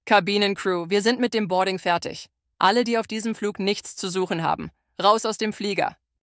BoardingComplete.ogg